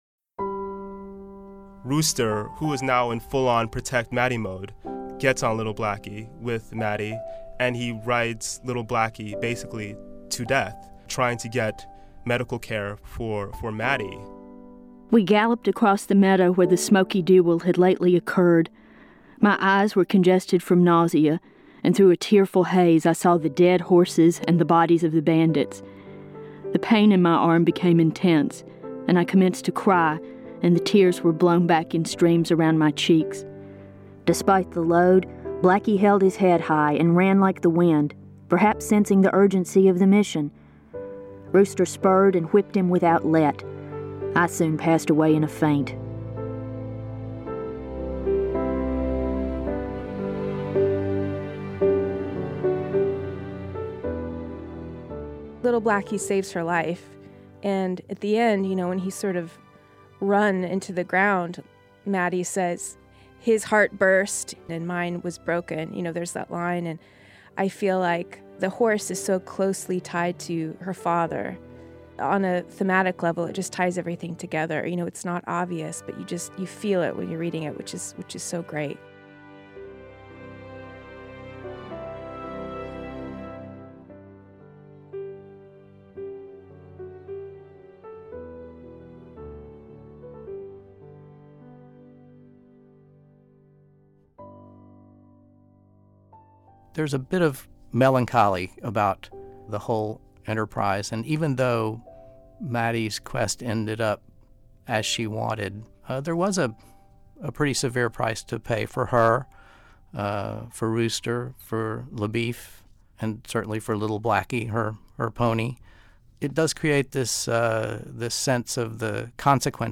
And let's not forget the voice of award-winning author, Donna Tartt, who reads an excerpt that brings Portis's words to life.